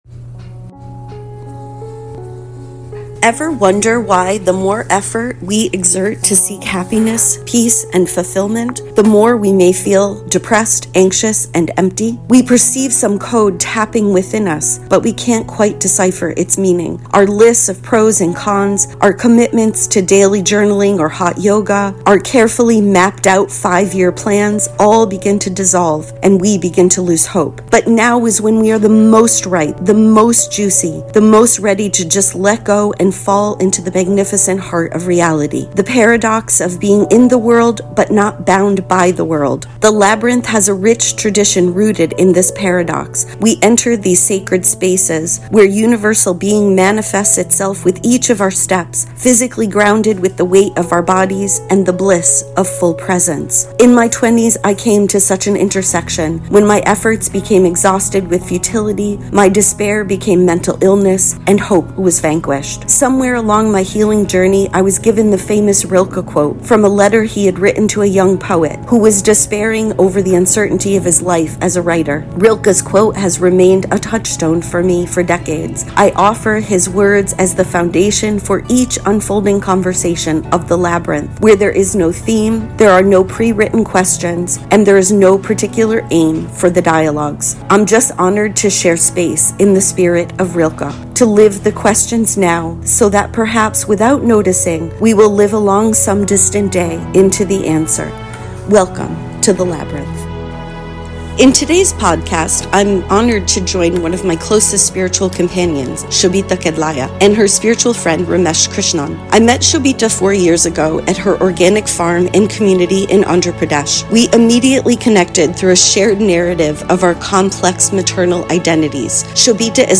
Go Ask A Tree: Conversation